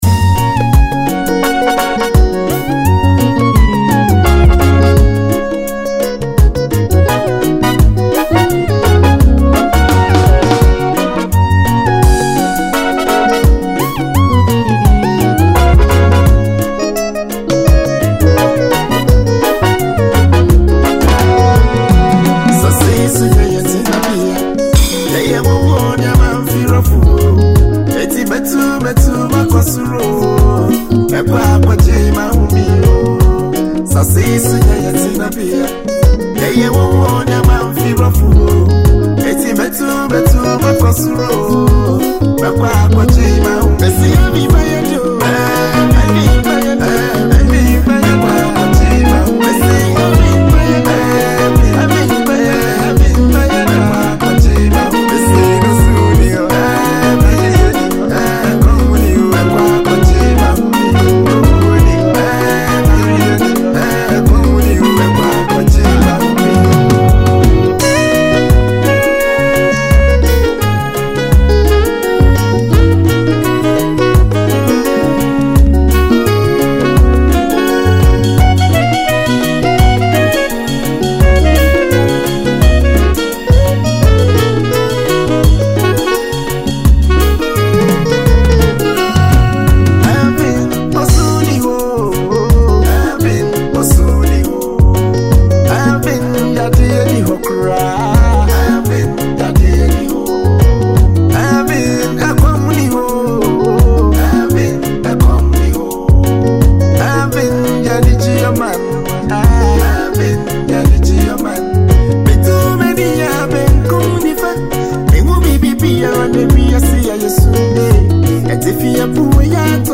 Ghanaian acclaimed faith singer